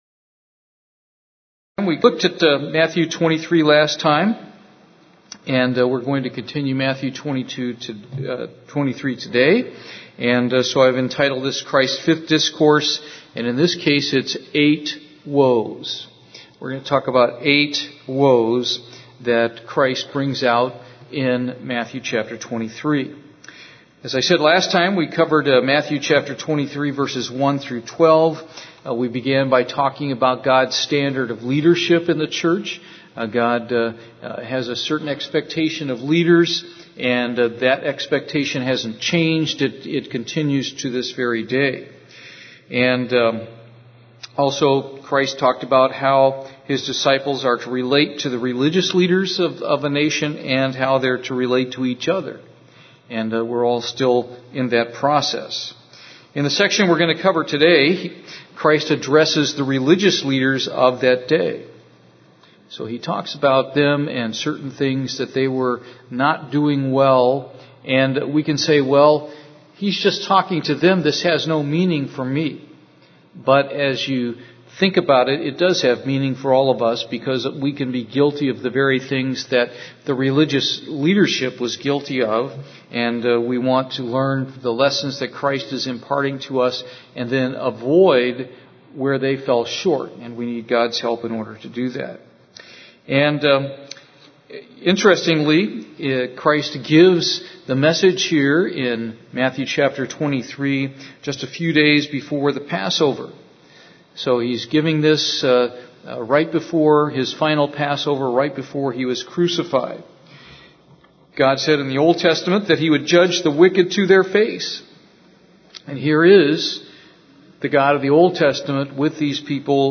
This sermon considers the eight judgments Jesus made on the leaders of the Jews in Matthew 23 where He began each one with the words "Woe unto you..."